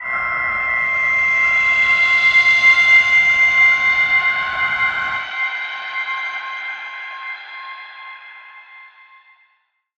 G_Crystal-C8-mf.wav